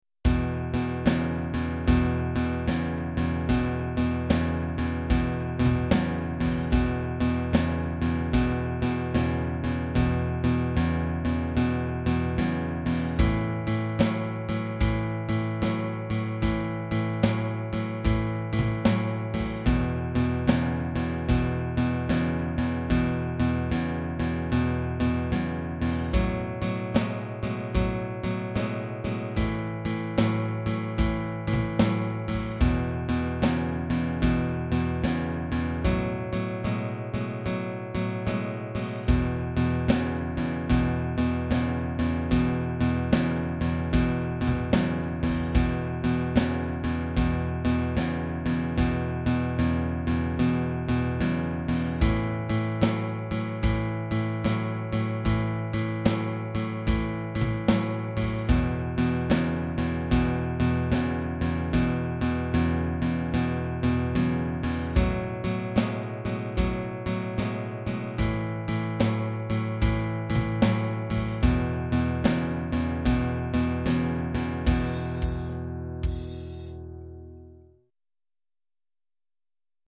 Open Position 5-6 Shuffle in E
5-6shuffleinEwithdrums.mp3